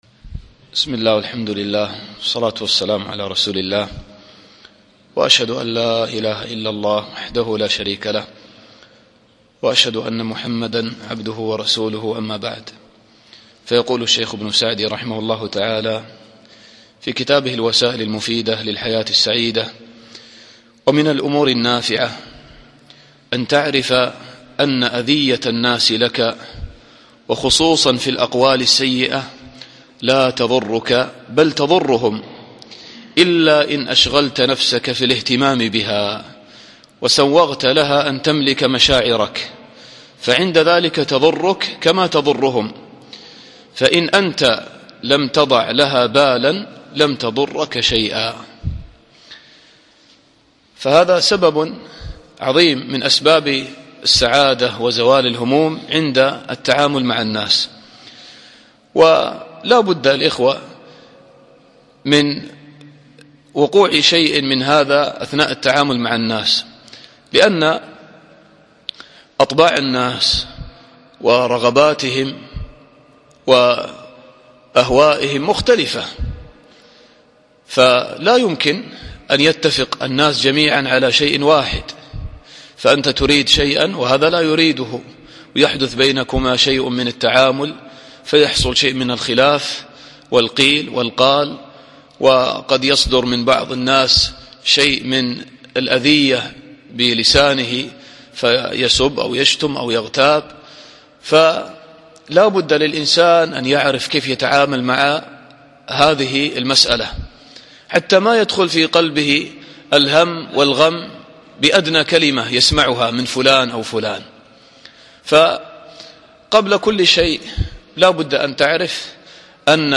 الدرس السابع عشر